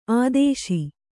♪ ādēśi